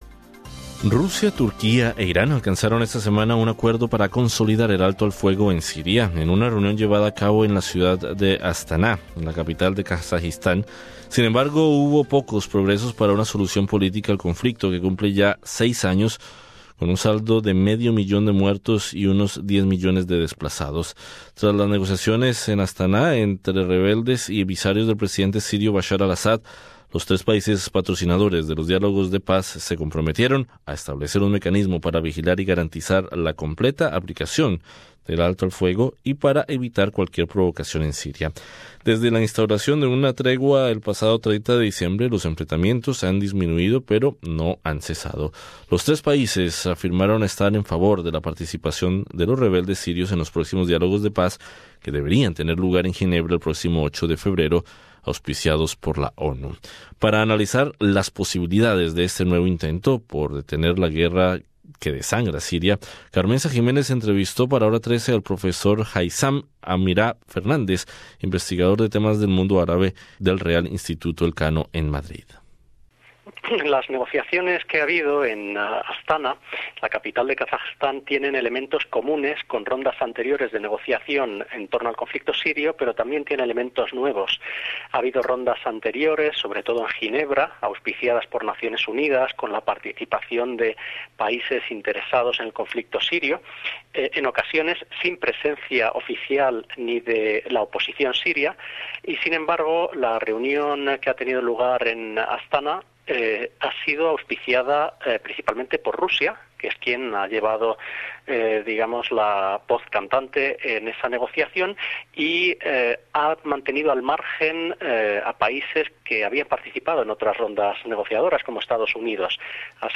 Rusia, Turquía e Irán alcanzaron esta semana un acuerdo para consolidar el alto al fuego en Siria, en una reunión llevada a cabo en la ciudad de Astaná, la capital de Kazajistán. Sin embargo, hubo pocos progresos para una solución política al conflicto que cumple ya 6 años, con un saldo de medio millón de muertos y unos 10 millones de desplazados. Entrevista